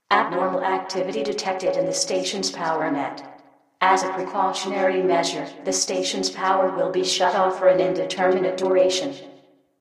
Makes the AI's voice more "AI"-like, adds a title screen, enables end of
poweroff.ogg